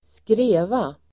Uttal: [²skr'e:va]